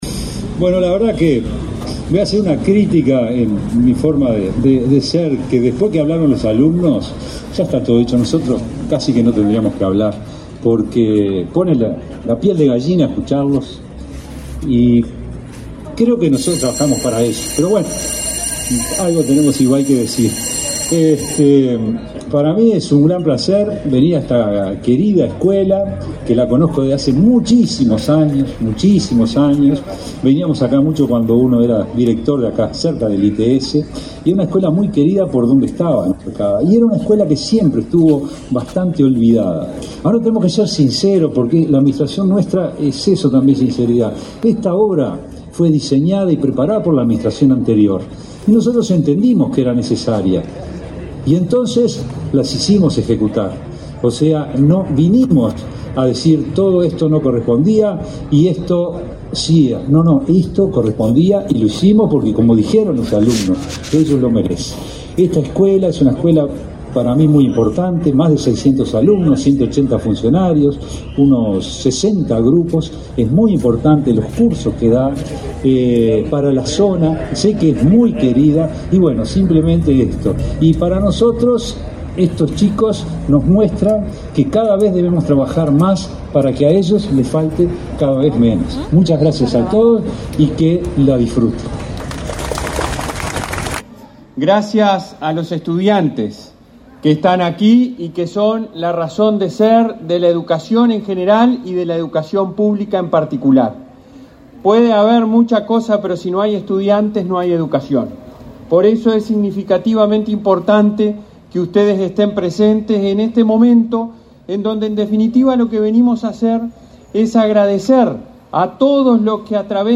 Conferencia de prensa por la inauguración de obras en la escuela técnica del barrio La Unión
La Dirección General de Educación Técnico Profesional-UTU de la Administración Nacional de Educación Pública (ANEP) inauguró, este 9 de mayo, las obras de ampliación, adecuación y acondicionamiento de la escuela técnica Andrés Bernardo Bruno, en el barrio La Unión, en Montevideo. Participaron en el evento el presidente del Consejo Directivo Central de la ANEP, Robert Silva, y el director de UTU, Juan Pereyra.